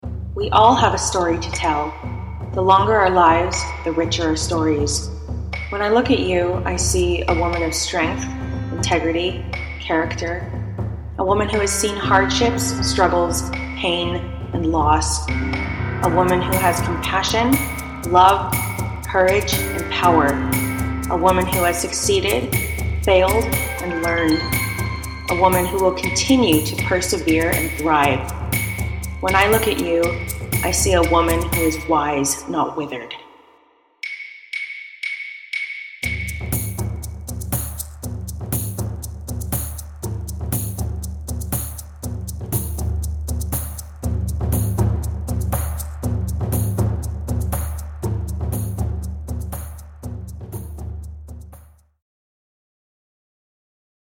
I wanted to make an intro for the podcast that had simple but compelling instrumentation and inspirational words.